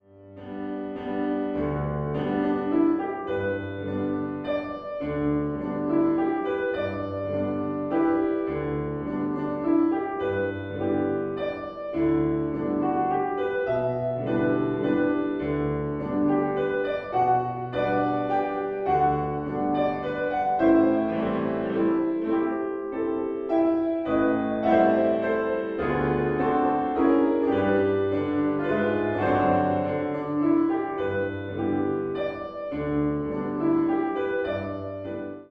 Klavier-Sound
Duett